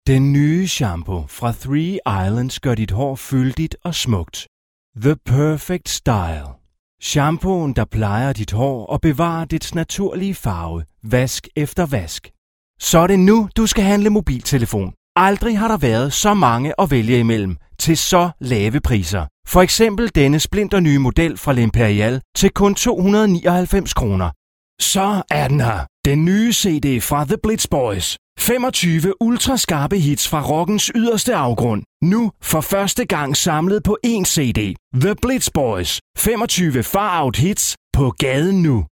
DA MM COM 01 Commercials Male Danish